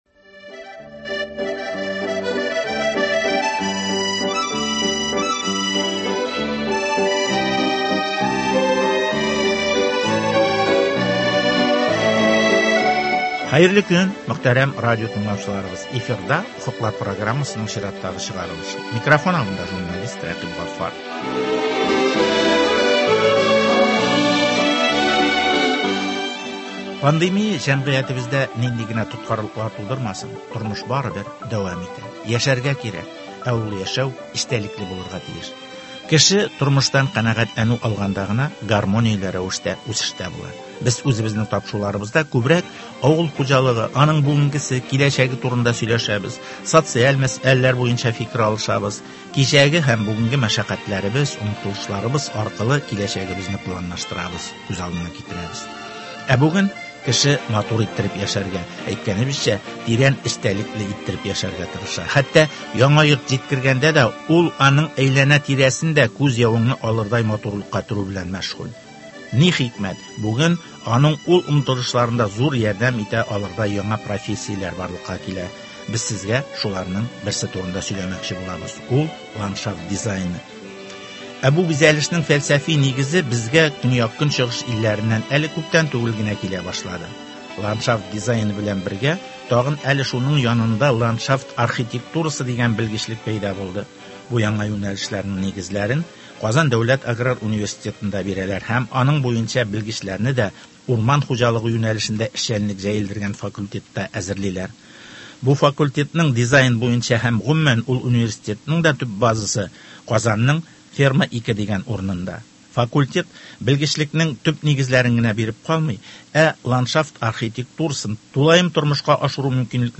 Ландшафт дизайны һәм ландшафт архитектурасы, аның буенча кадрлар әзерләү мәсьәләләре хакында Казан дәүләт аграр университеты җитәкчелеге һәм белгечләре белән әңгәмә.